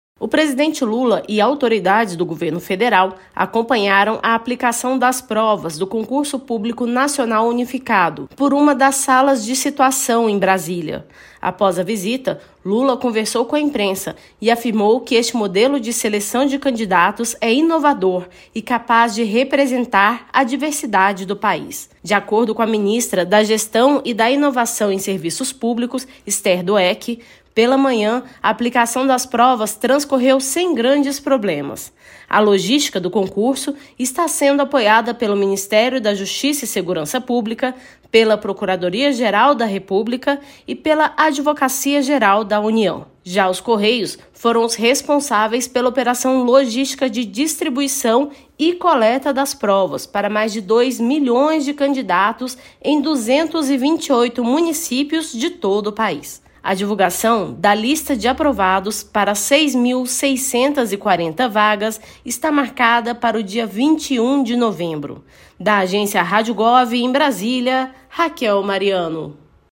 Ao conversar com a imprensa, Lula destacou o modelo inovador e incluso de seleção de novos servidores públicos.